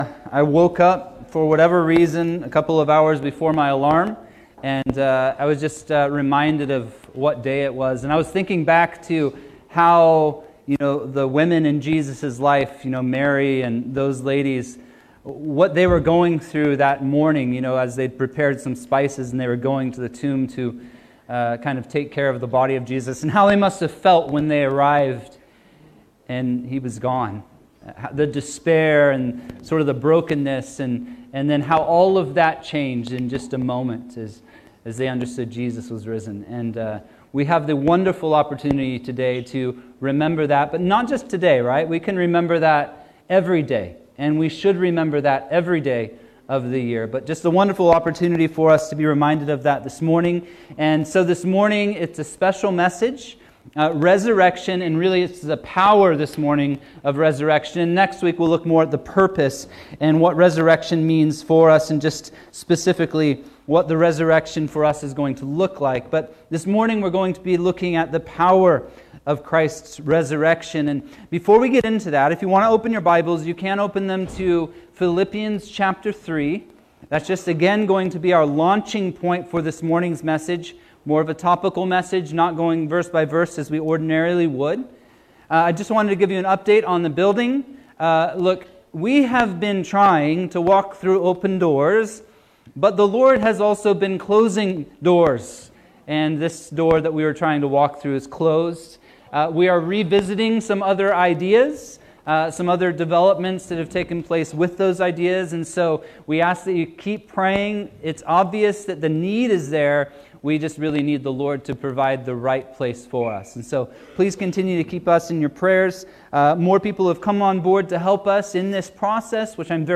Sermons | Shoreline Calvary